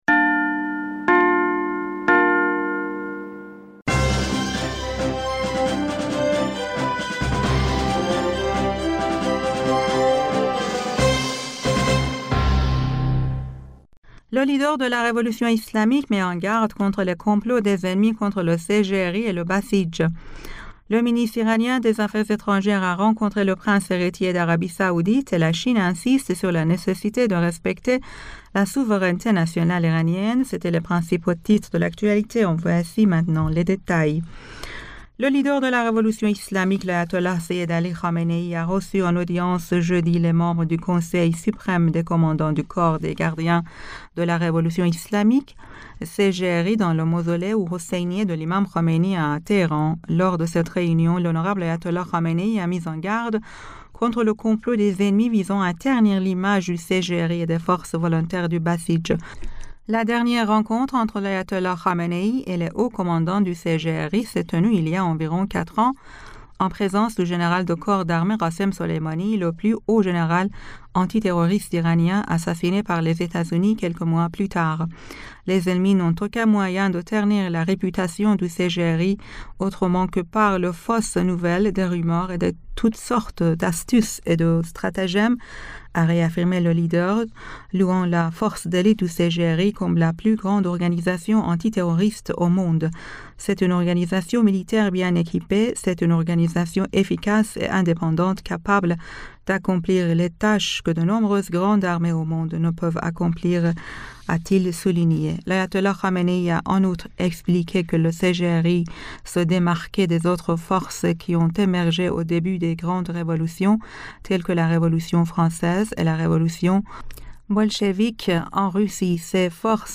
Bulletin d'information du 18 Aout 2023